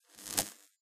sound / effects / sparks2.ogg
sparks2.ogg